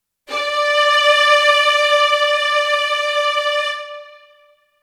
素材は倍音を多く含みますバイオリンです。
▼　wave unedits　▼